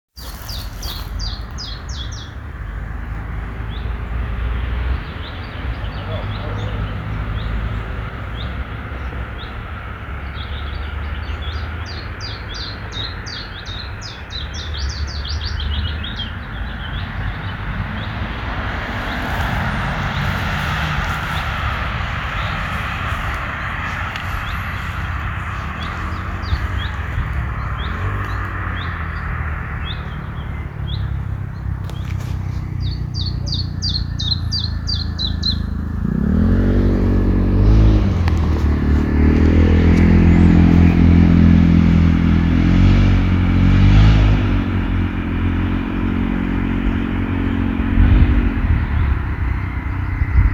Daarnaast was een mengzingende Fitjaf of Tjiftis (Tjiftjaf/Fitis) erg leuk!